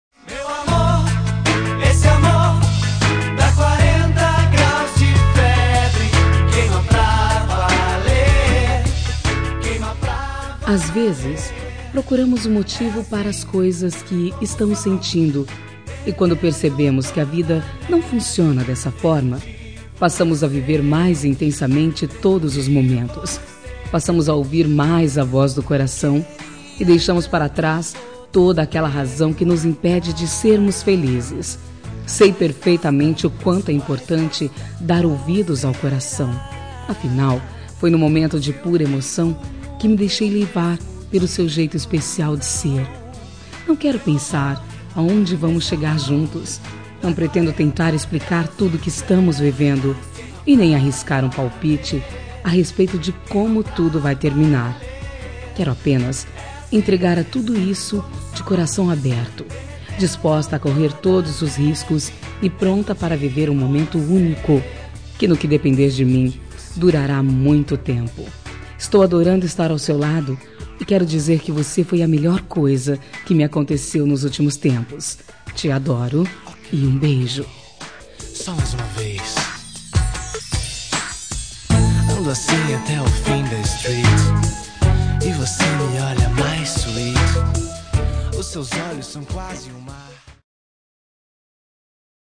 Telemensagem Início de Namoro – Voz Feminina – Cód: 749